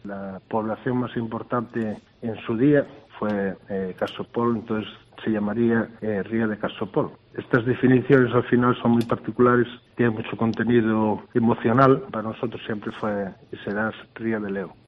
Declaraciones de César Álvarez, alcalde de Vegadeo